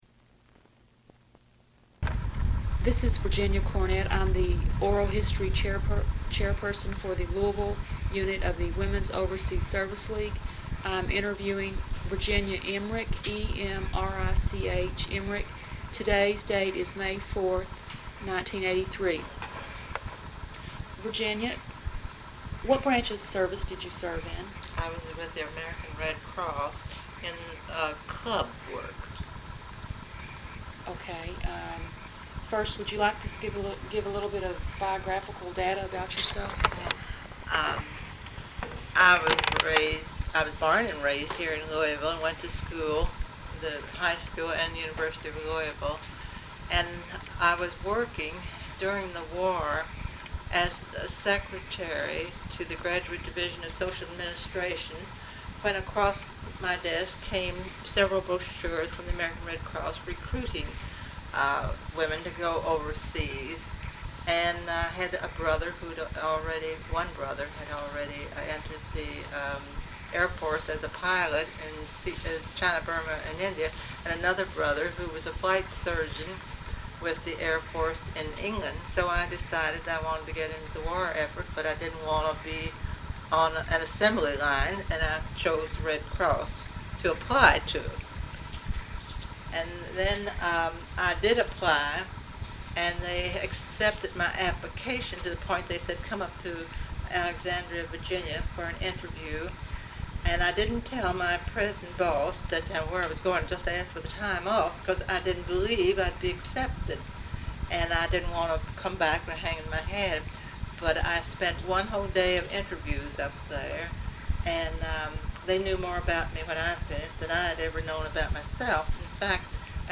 Interview
Material Type Sound recordings Interviews